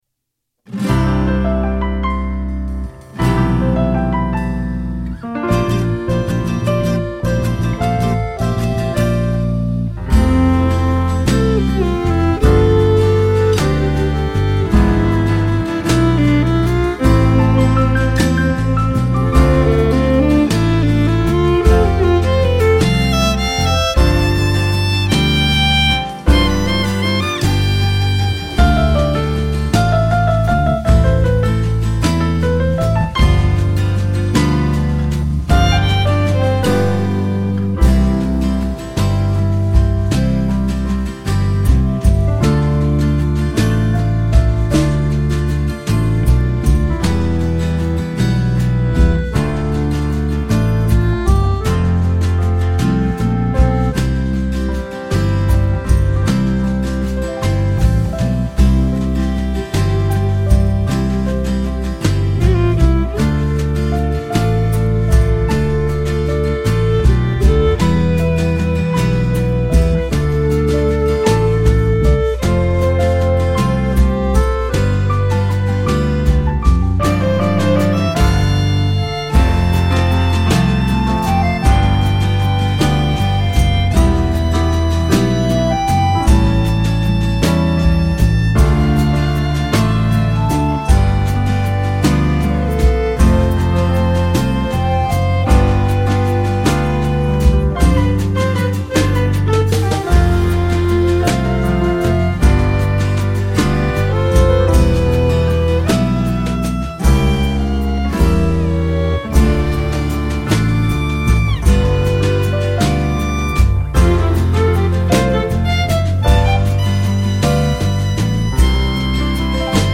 hino_sinodo_diocesano_playback.mp3